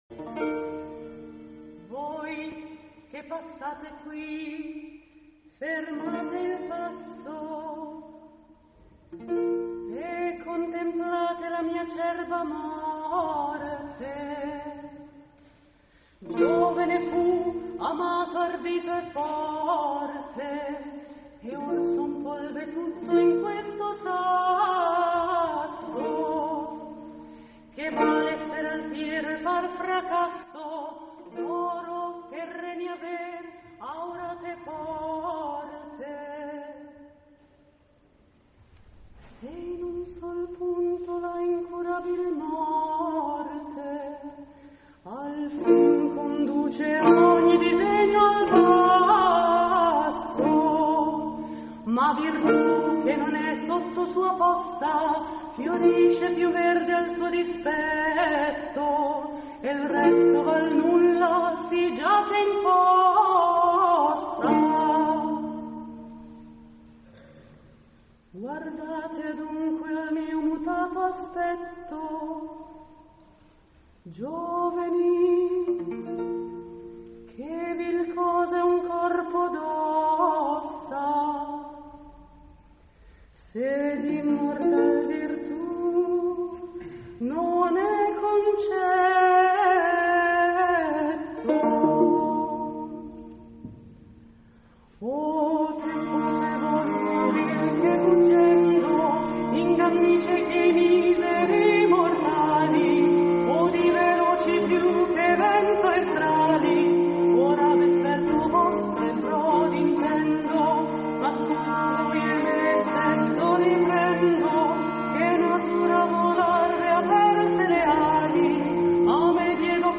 La proposta tutta medievale